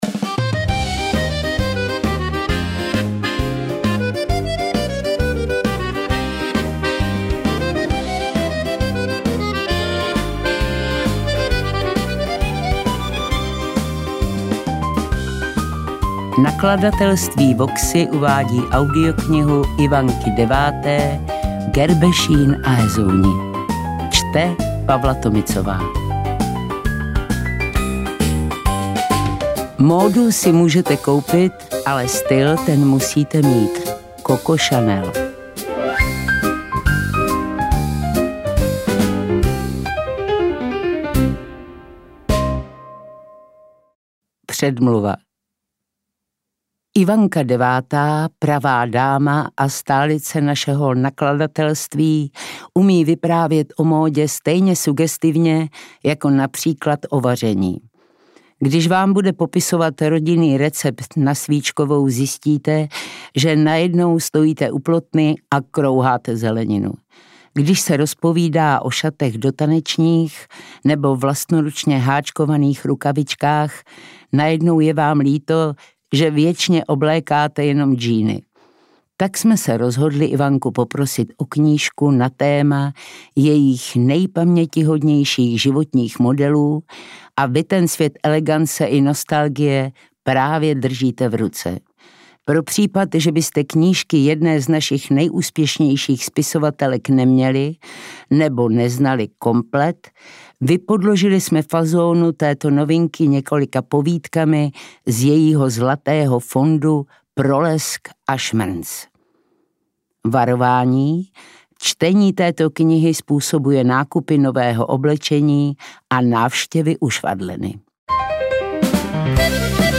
Interpret:  Pavla Tomicová
AudioKniha ke stažení, 29 x mp3, délka 3 hod. 43 min., velikost 202,4 MB, česky